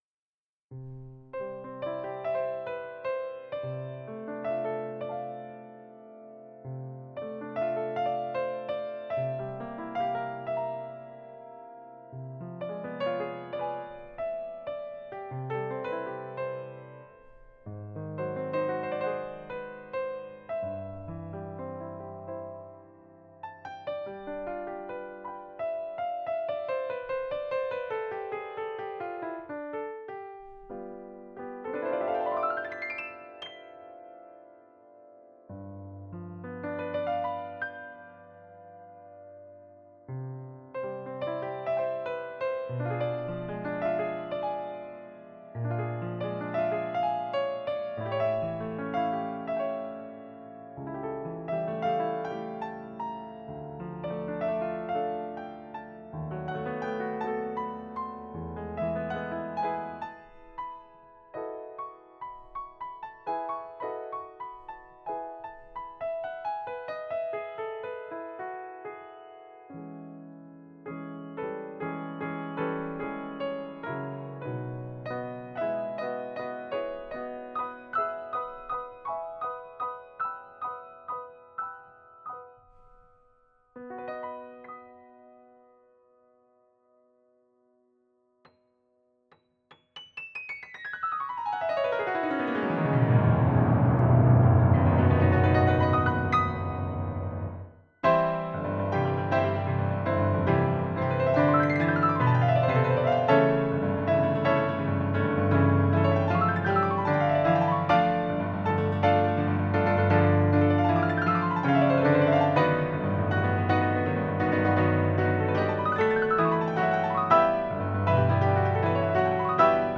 Sampled Grand Piano with Karma!
The instrument reacts just as a real piano; stunts like repedalling are no problem.
The playing of this Kontakt instrument brings up a colorful and warm sound, that is despite its nice depth and ambience still direct enough to create a convincing „real piano playing“ illusion.
Occasional „sloppy“ notes represent this old lady and have not been banned to keep up the true charm of this instrument.
Listen to our dry, linear
Demos with just a slight brickwall limiting in the output stage